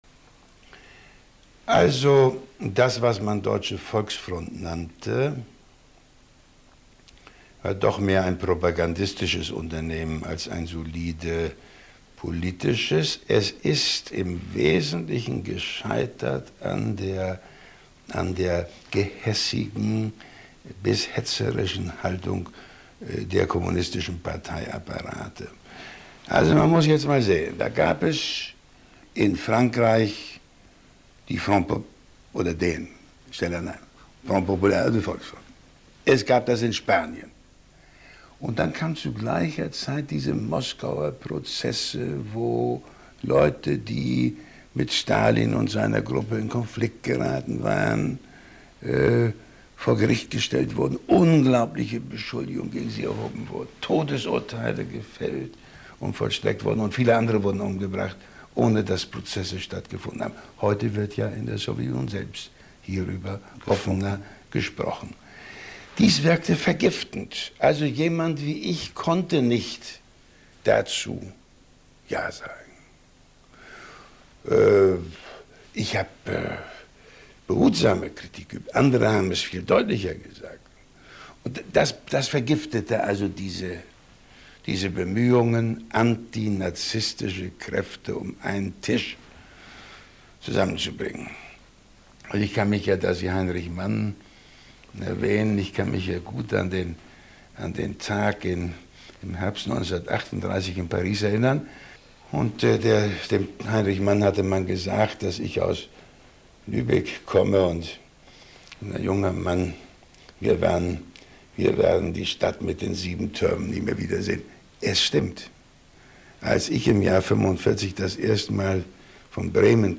Excerpt from an interview with Willy Brandt